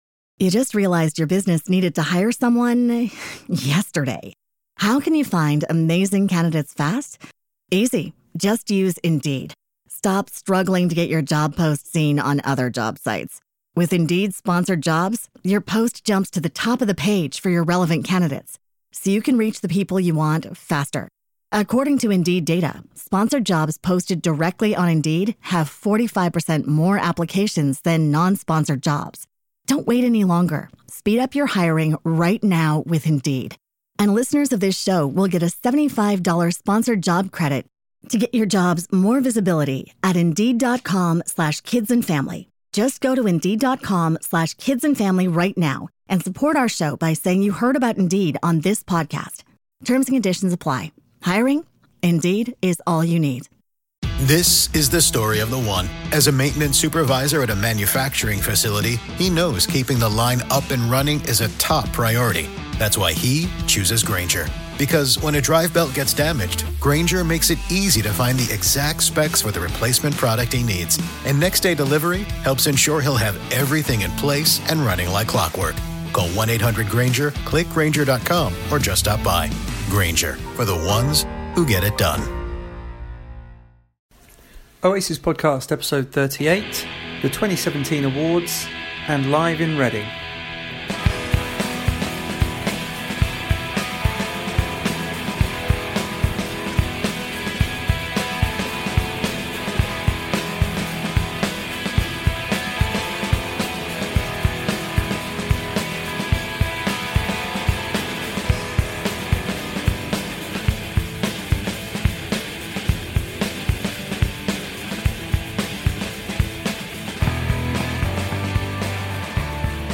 Interview with Oas-is